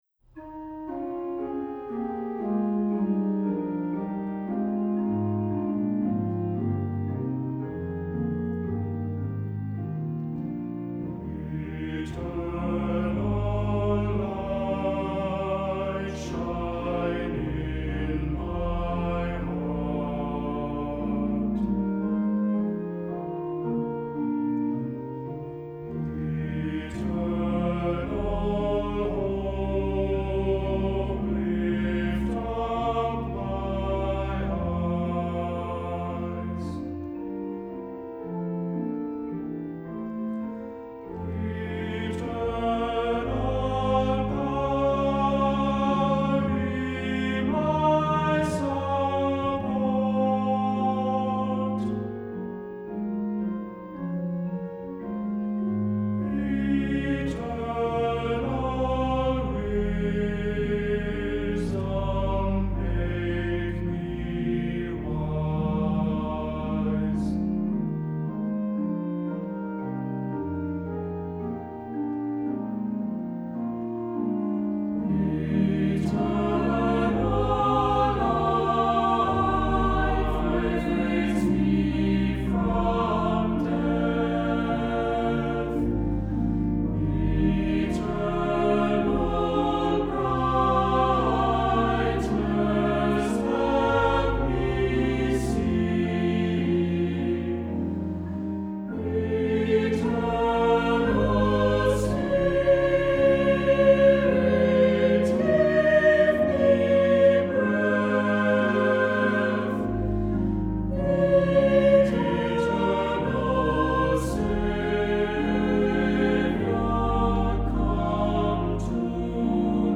Accompaniment:      Organ
Music Category:      Christian